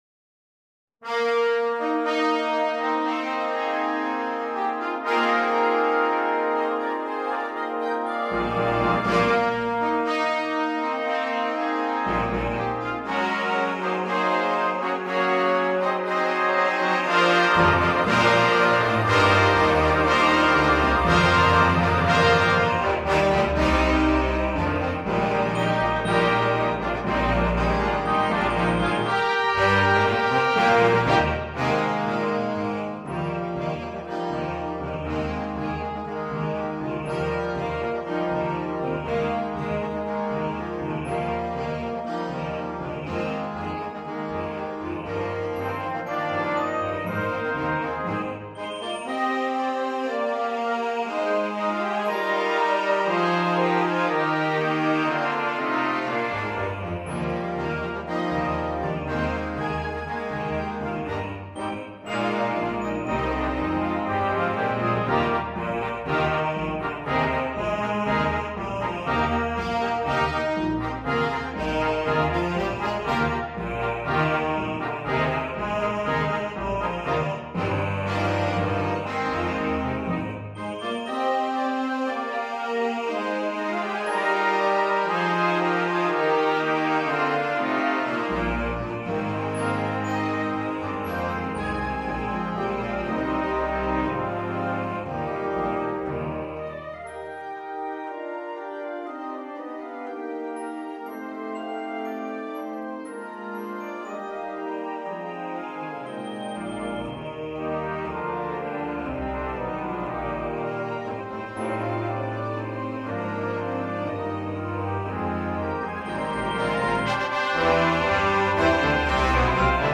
Volledige band
zonder solo-instrument
originele compositie, Lichte muziek